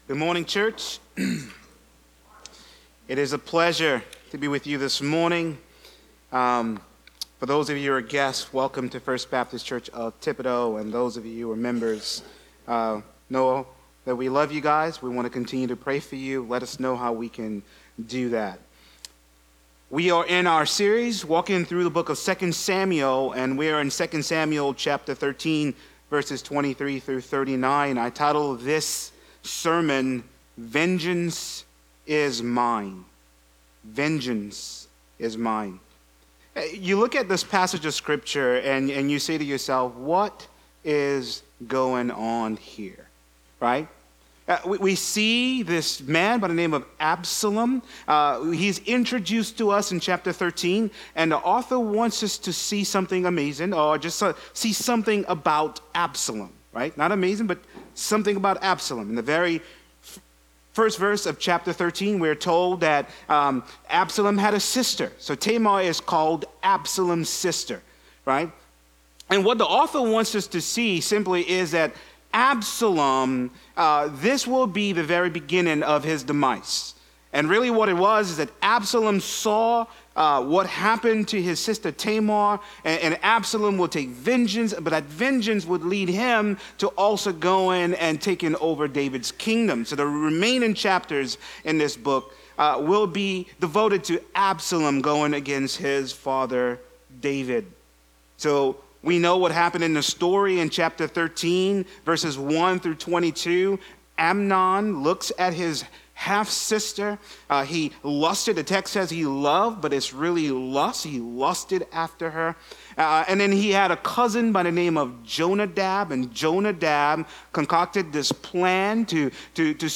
A message from the series "The Book of 2 Samuel."